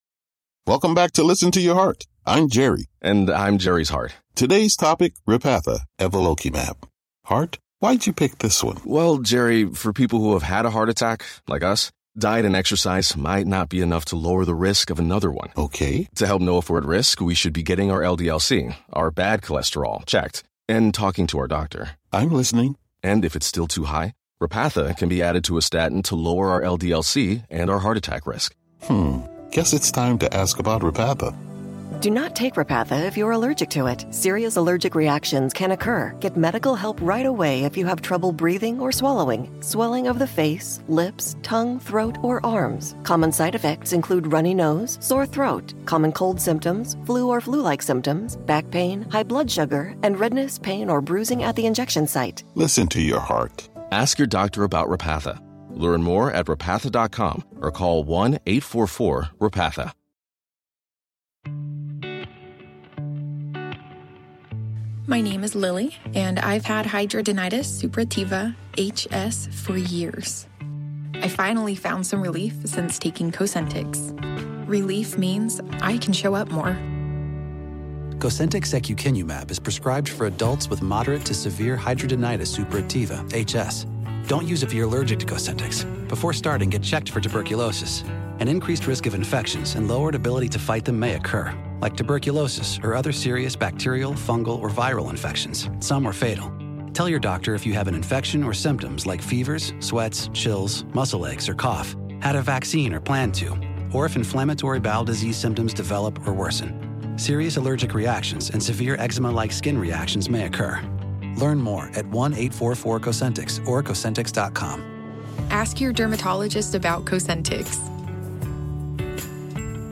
Lusk Dad, Who Drives Kids Uninsured, Gets Snitched On During Live Debate - 15.10.2024